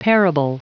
Prononciation du mot parable en anglais (fichier audio)
Prononciation du mot : parable